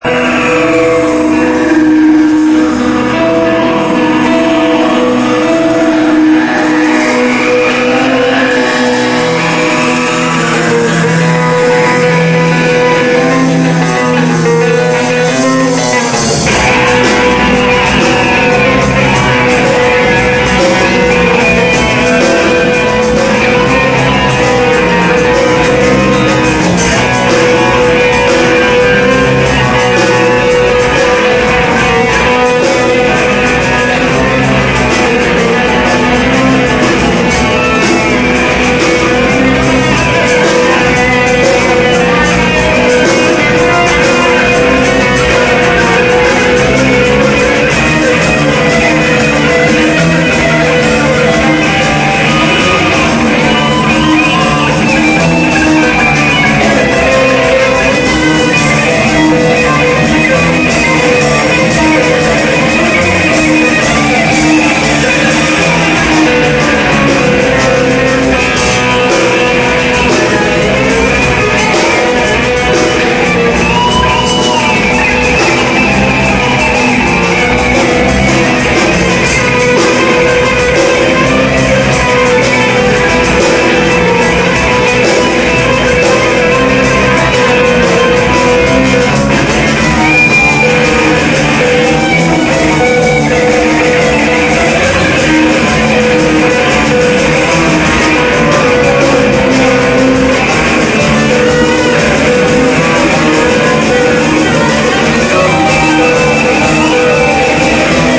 EN VIVO!!!